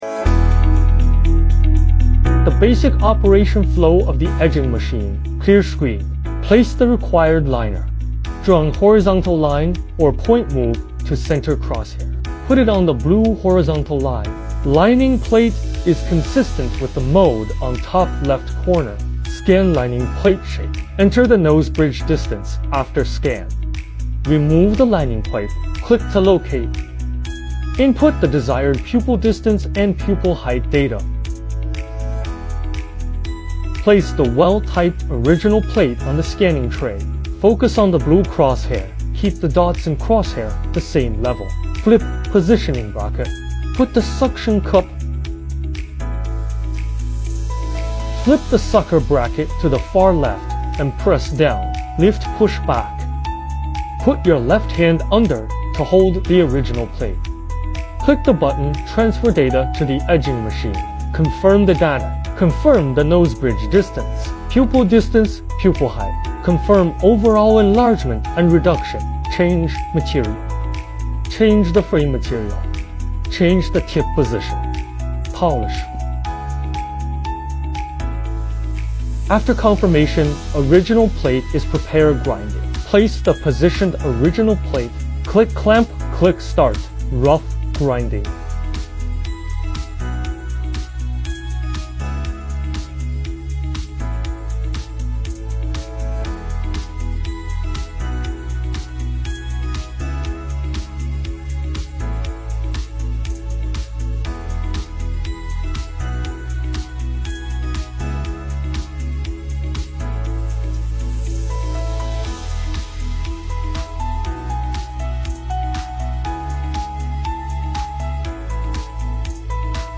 New automatic edge grinding machine